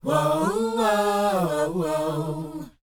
WHOA D#C U.wav